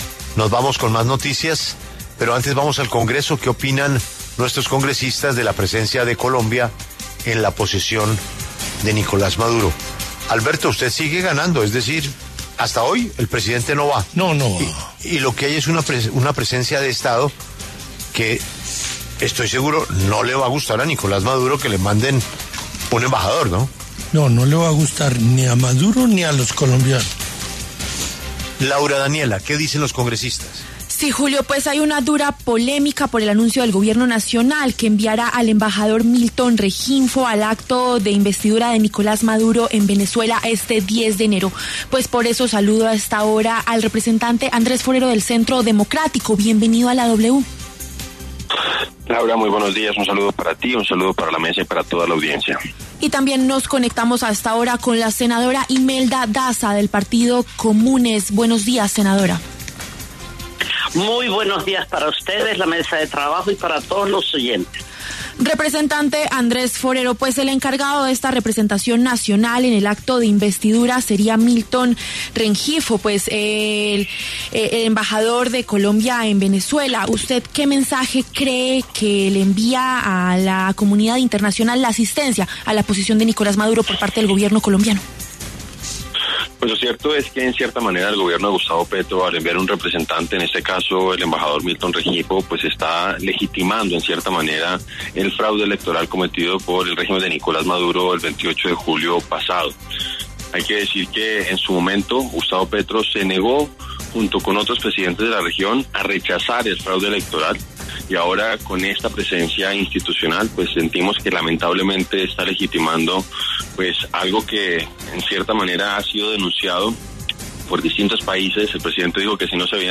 El representante Andrés Forero, del Centro Democrático, y la senadora Imelda Daza, del Partido Comunes, pasaron por los micrófonos de La W.